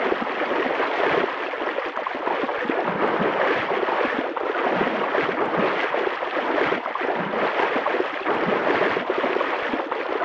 Sfx_creature_spinner_swim_01.ogg